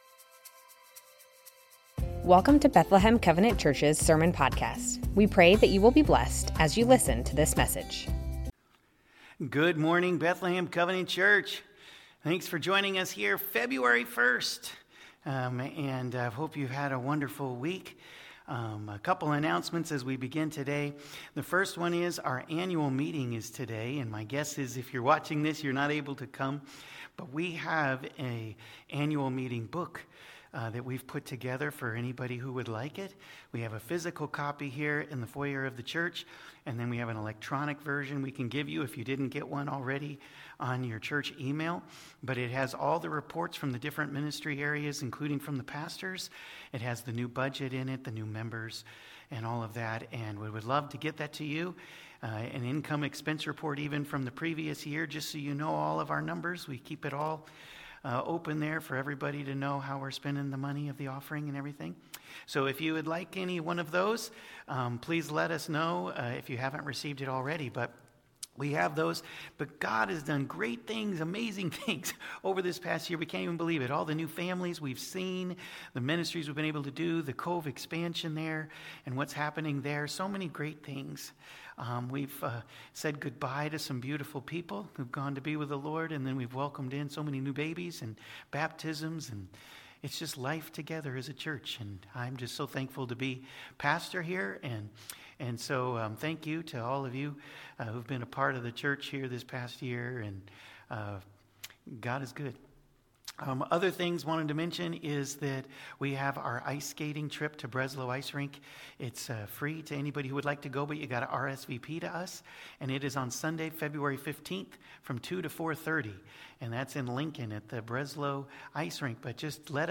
Bethlehem Covenant Church Sermons The Ten Commandments - Sabbath Feb 01 2026 | 00:37:51 1x 00:00 / 00:37:51 Subscribe Share Spotify RSS Feed Share Link Embed